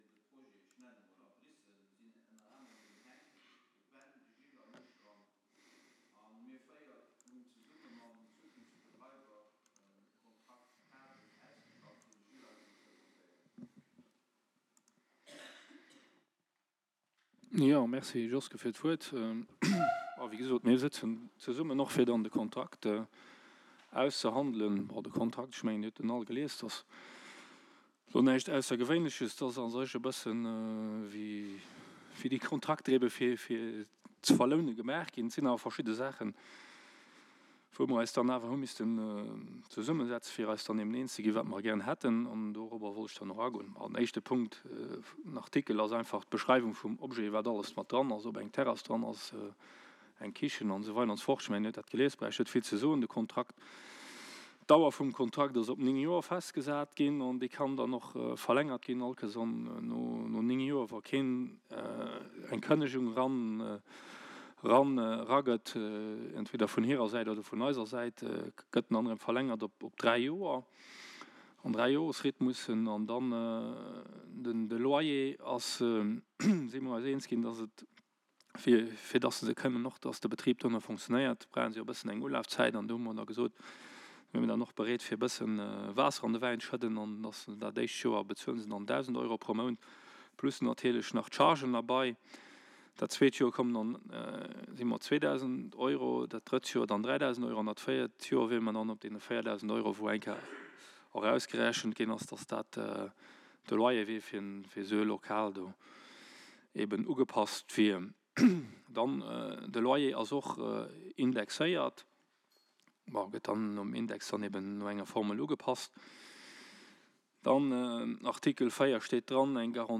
Séance publique du Conseil Communal du 22 décembre 2022 - Helperknapp
En séance publique :